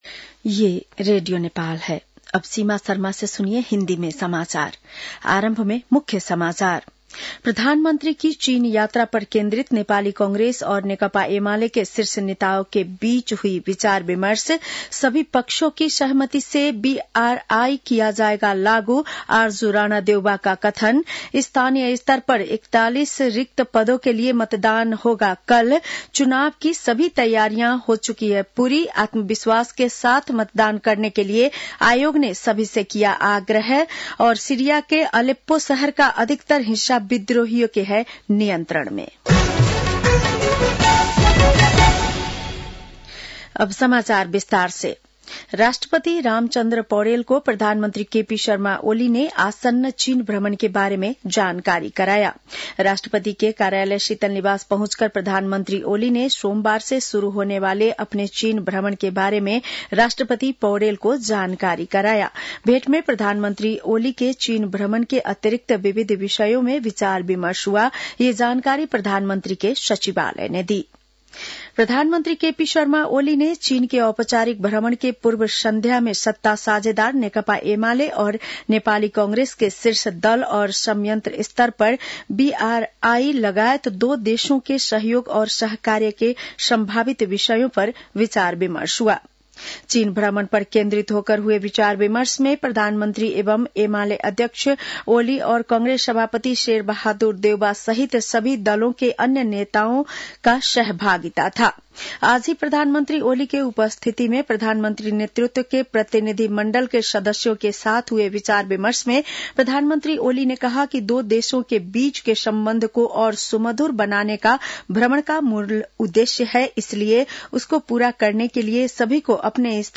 बेलुकी १० बजेको हिन्दी समाचार : १८ पुष , २०२६